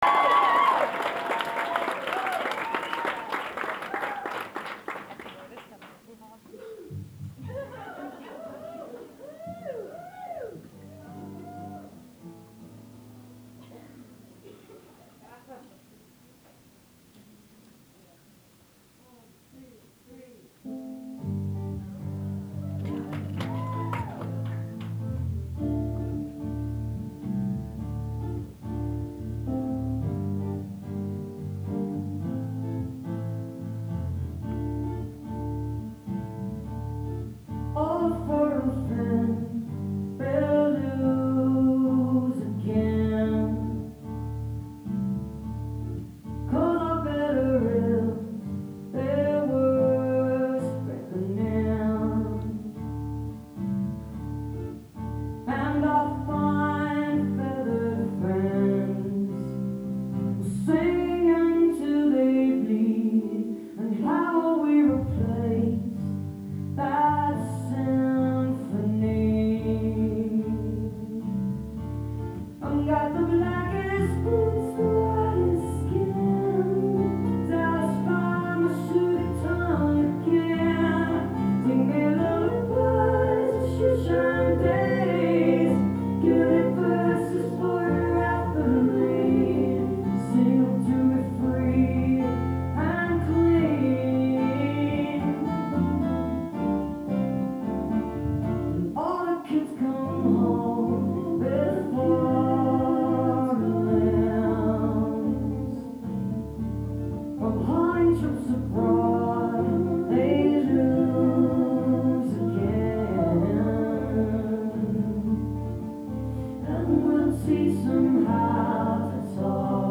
birchmere music hall - alexandria, virginia